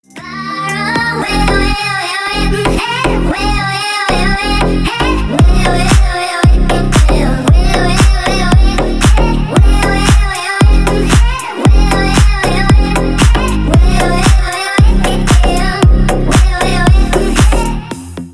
• Песня: Рингтон, нарезка
Прикольный ремикс на звонок